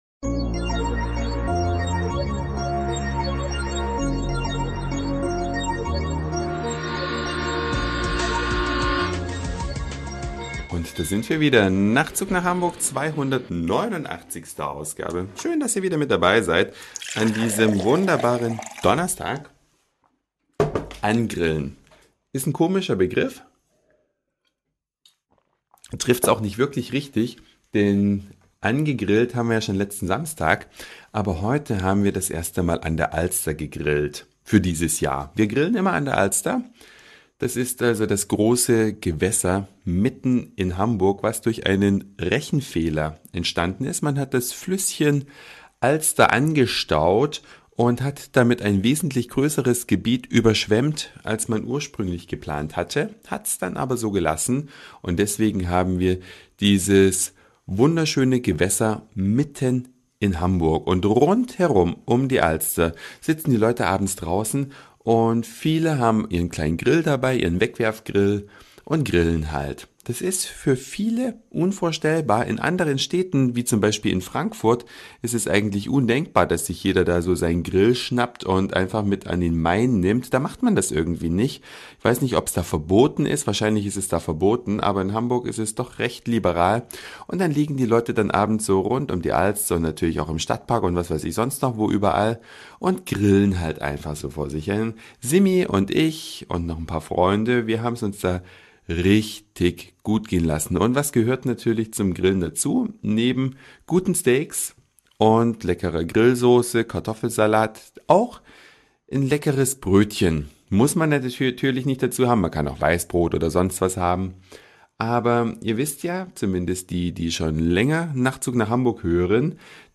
Eine Reise durch die Vielfalt aus Satire, Informationen, Soundseeing und Audioblog.